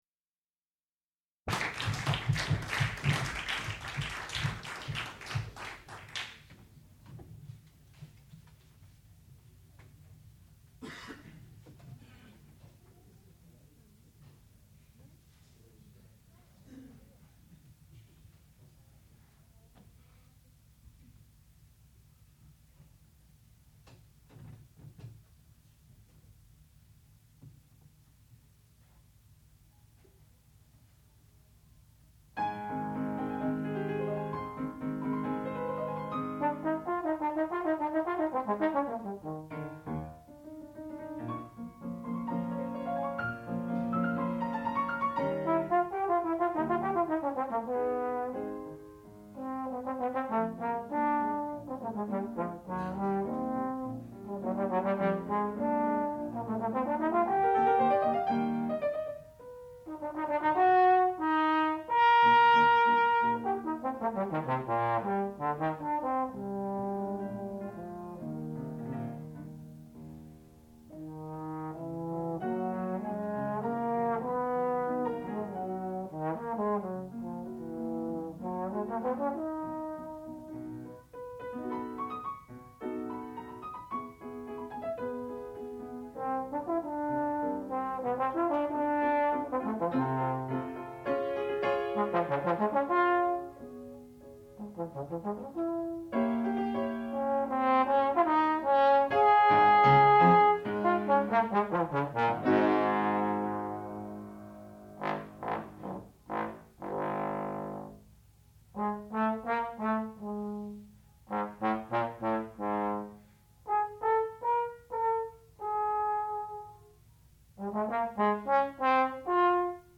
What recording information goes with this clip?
Qualifying Recital